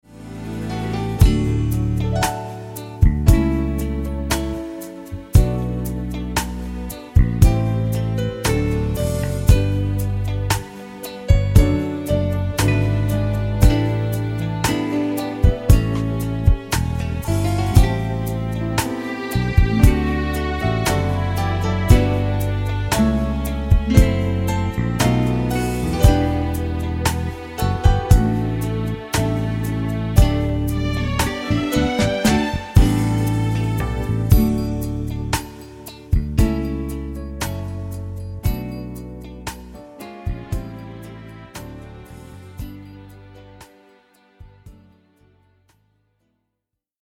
Besetzung: S.A.T.B./S.A.M./S.S.A., Solo, Piano